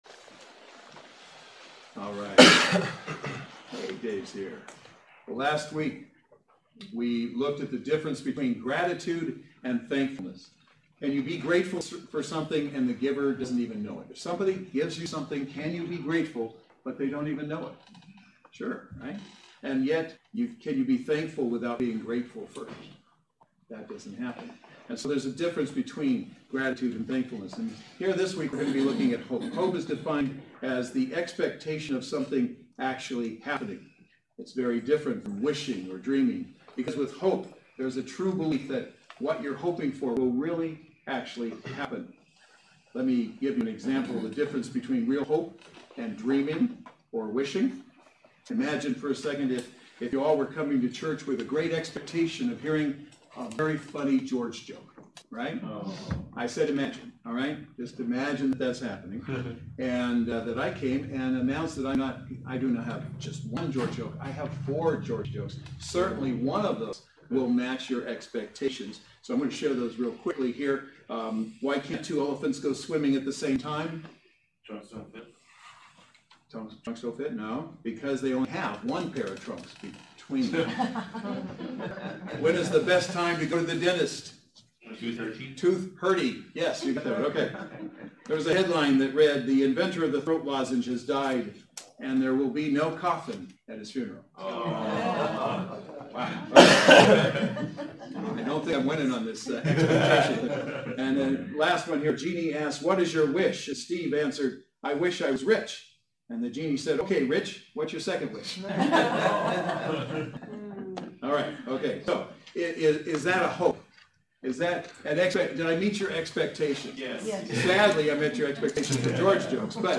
Christmas Firsts Service Type: Saturday Worship Service Speaker